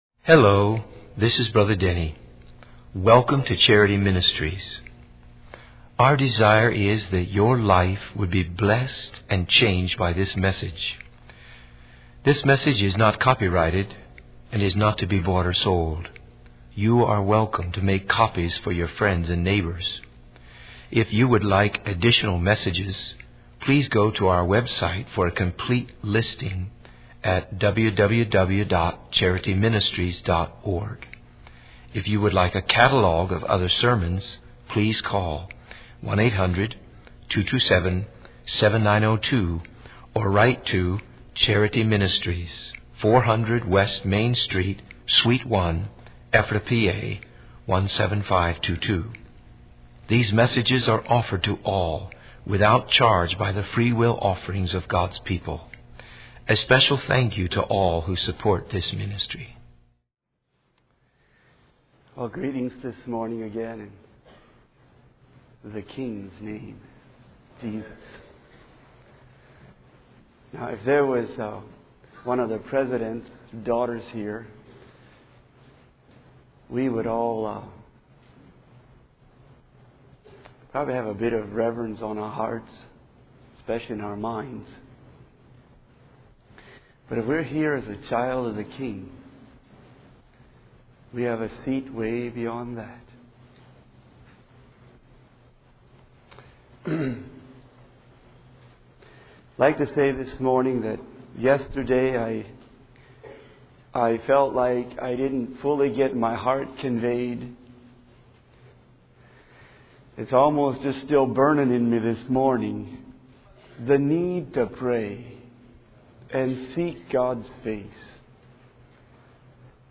In this sermon, the speaker emphasizes the importance of consistently reading and studying the Bible. He uses the analogy of laying four bricks a day to illustrate the idea of constructively building one's life for Jesus Christ. The speaker encourages listeners to make Jesus the Lord of their lives, seek Him diligently, and read the Bible with purpose and intention.